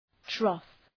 Προφορά
{trɒf}